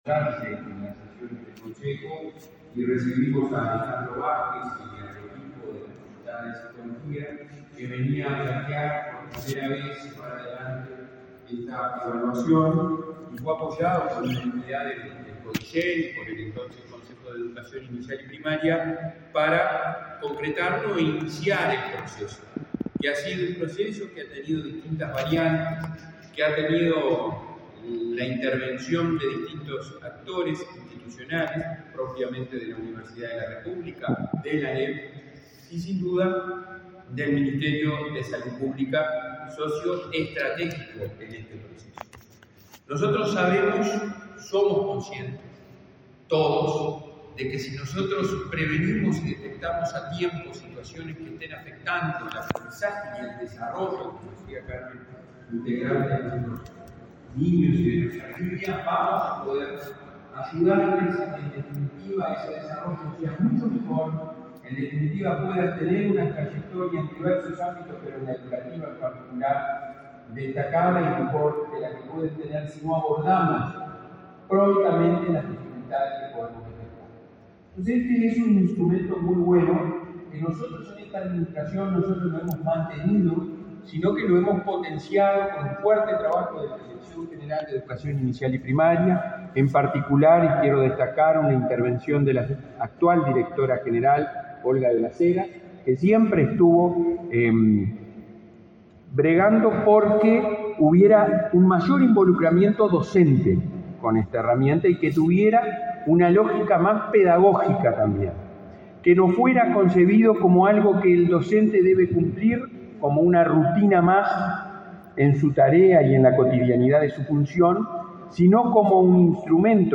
Palabras del presidente de la ANEP, Robert Silva